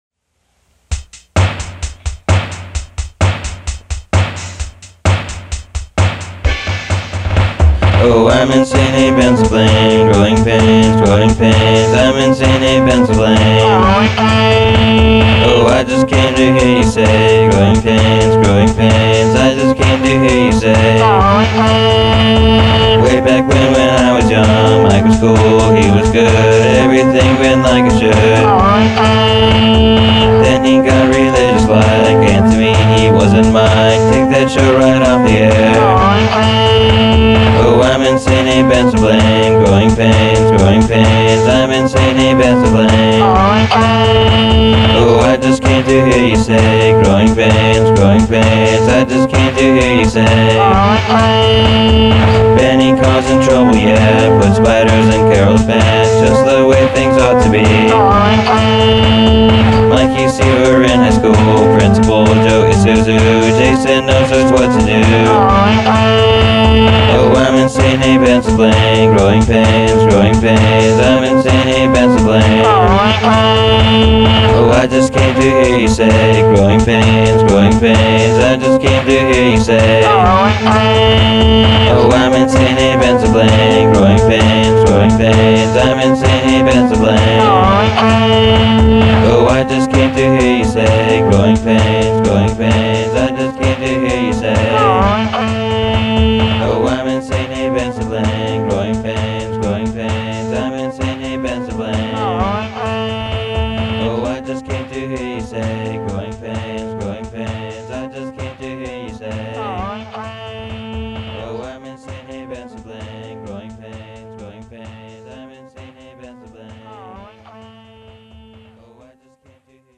(Super Hi-Fi, Studio Produced Sellout Quality)